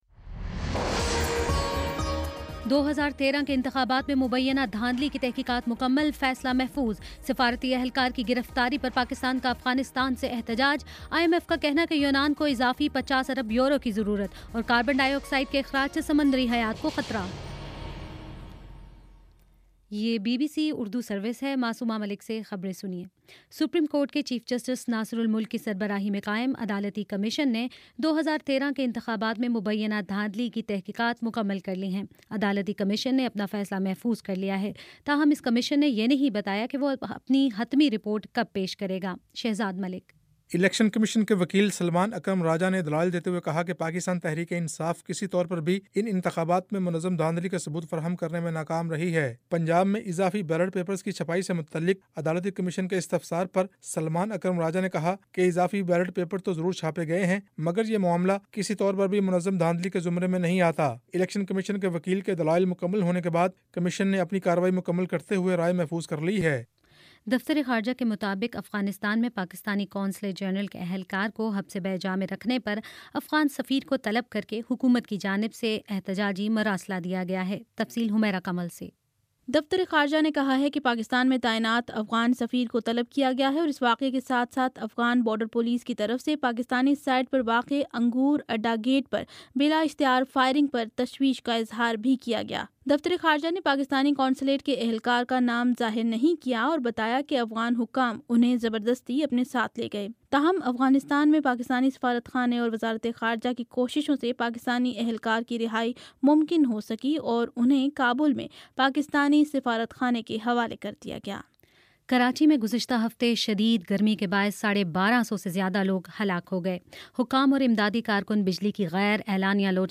جولائی 3: شام چھ بجے کا نیوز بُلیٹن